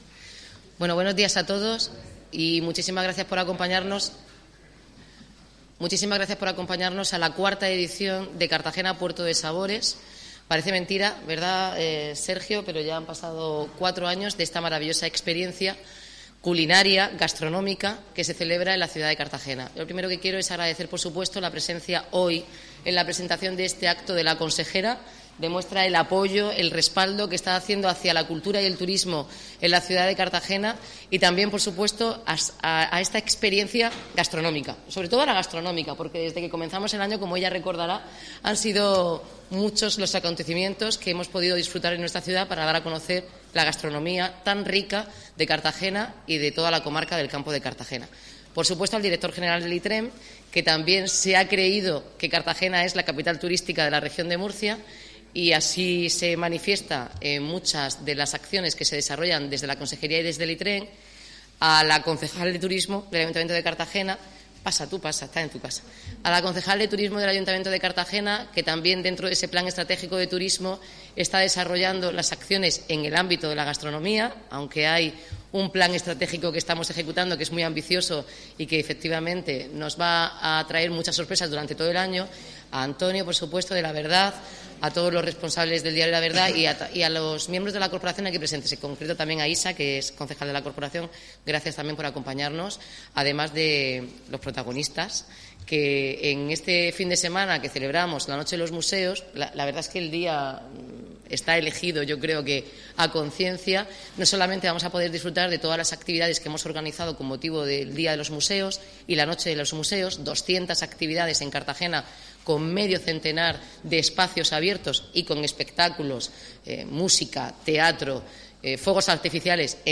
Enlace a Presentación de la IV edición de Cartagena Puerto de Sabores